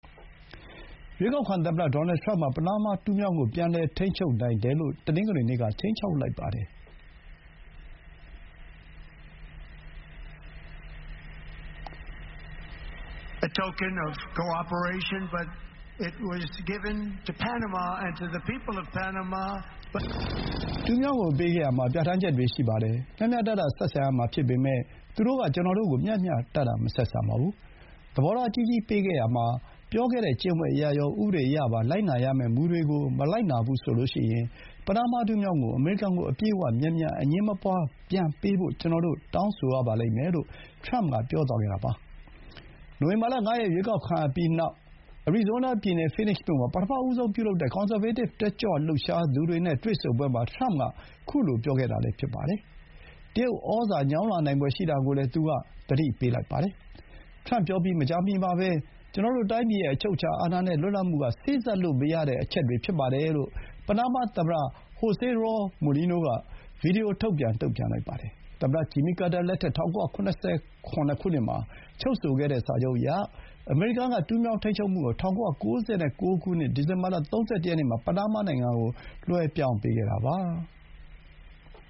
Phoenix မြို့ မှာ ပထမဆုံး ပြုလုပ်တဲ့ ကွန်ဆာဗေးတစ် တက်ကြွလှုပ်ရှားသူတွေ နဲ့ တွေ့ဆုံပွဲမှာ မိန့်ခွန်းပြောနေတဲ့ ရွေးကောက်ခံသမ္မတ ထရမ့် (ဒီဇင်ဘာ ၂၂၊ ၂၀၂၄)